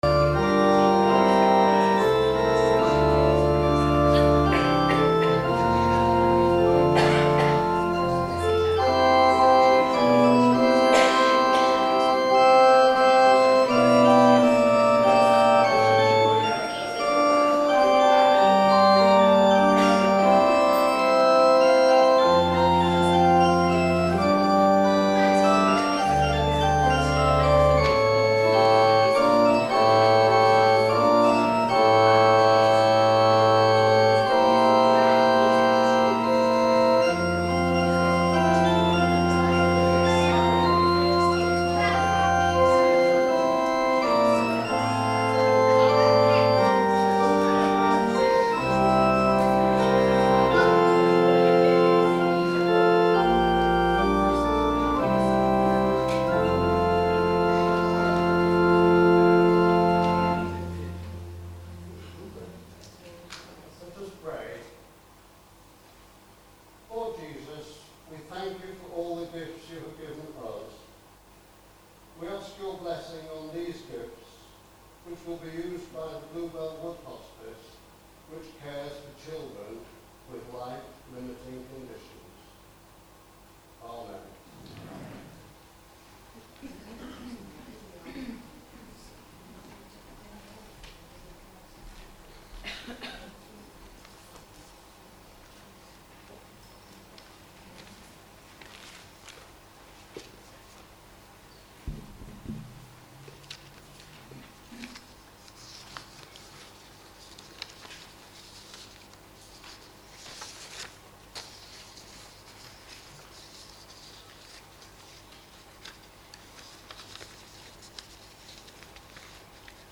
the choir and our congregation and enjoy our traditional local carol festival. Some old, some newer and some with a local twist.
Christ-Church-Choir-Local-Carols-2-Local-Carols-2.mp3